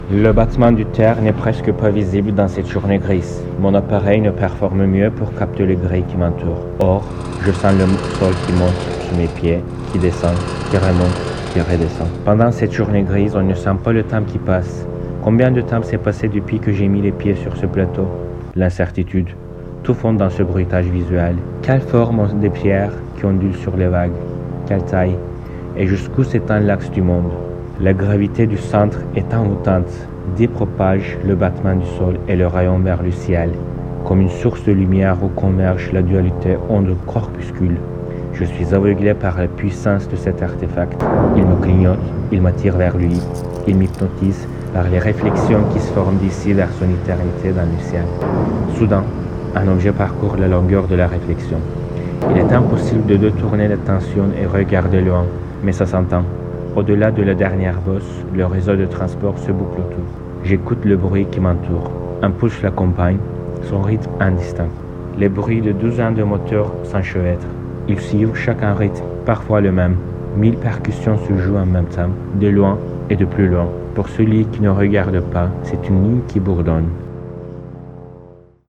Au-delà de la dernière bosse, le réseau de transport se boucle autour. J’écoute le bruit qui m’entoure. Un pouls l’accompagne, son rythme indistinct.
Les bruits de douzains de moteurs s’enchevêtrent.
Mille percussions se jouent en même temps, de loin et de plus loin. Pour celui qui ne regarde pas c’est une ligne qui bourdonne.